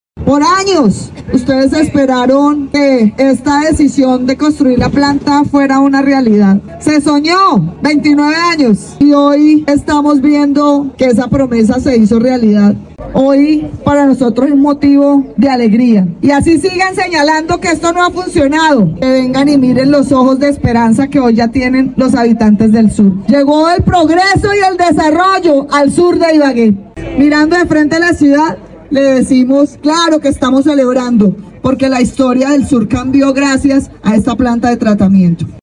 Alcaldesa-de-Ibague-Johana-Aranda.mp3